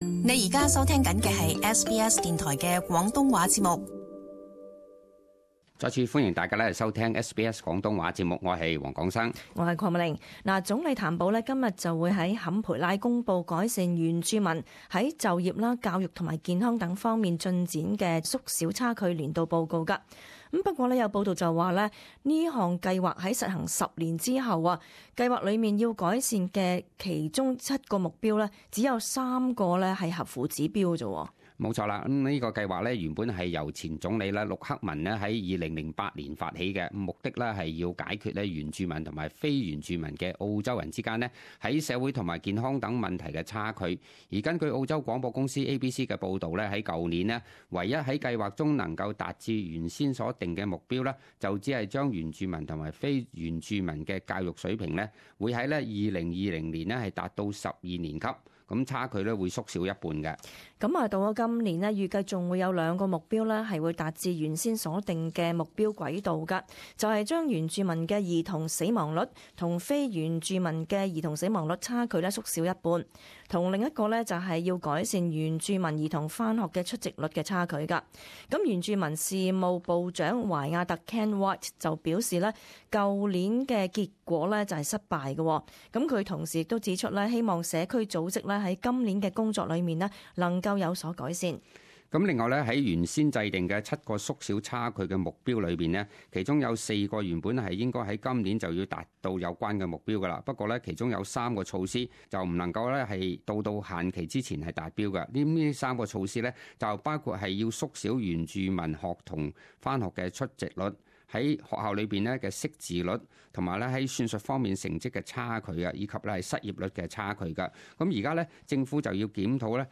【時事報導】改善原住民計劃未達標 04:45 Prime Minister Malcolm Turnbull at the Close the Gap parliamentary breakfast event at Parliament House in Canberra on Thursday.